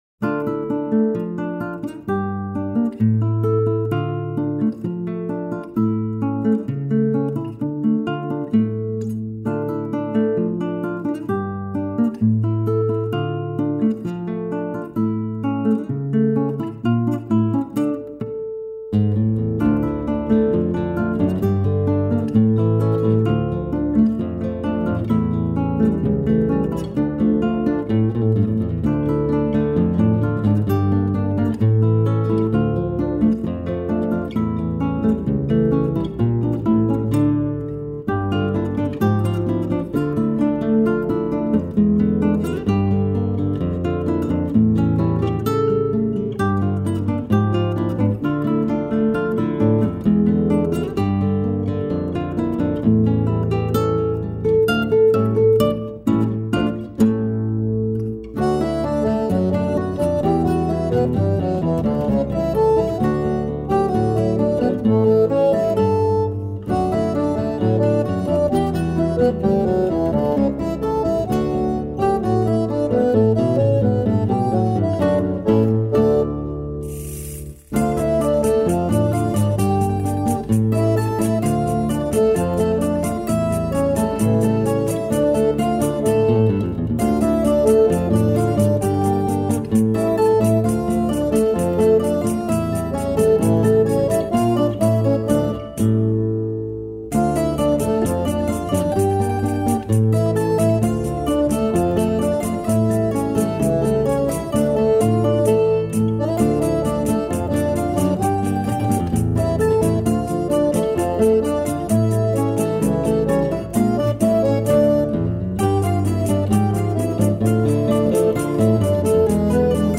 2954   02:26:00   Faixa: 7    Samba